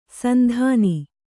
♪ sandhāni